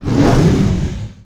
hurt1.wav